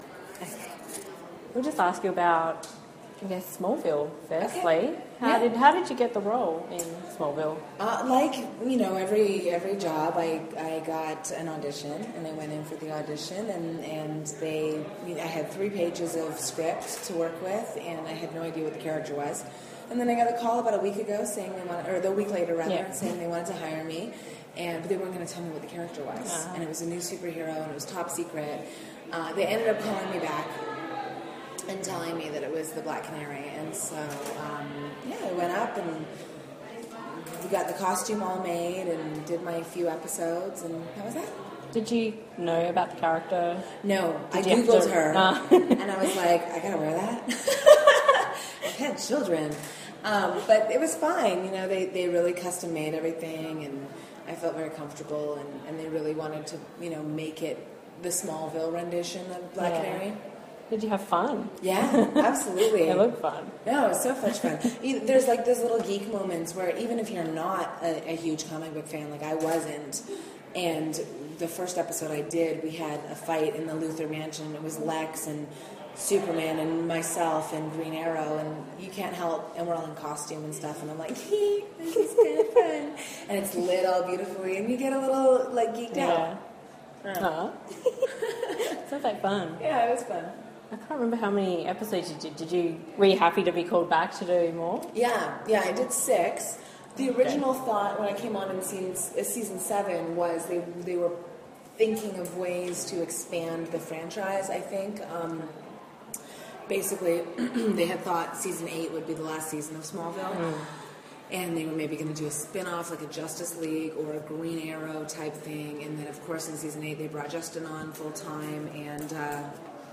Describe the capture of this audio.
On the weekend of October 22nd-23rd Armageddon Expo held their yearly Melbourne show, I was on hand to cover the event for our site.